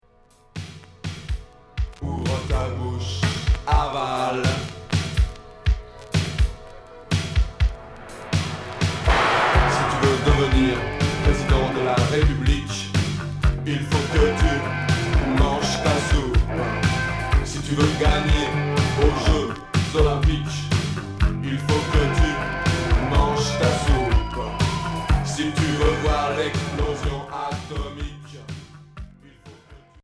Rock New wave Unique 45t retour à l'accueil